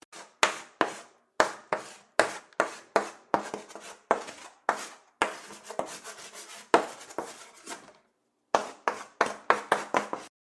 Скрип мела по деревянной доске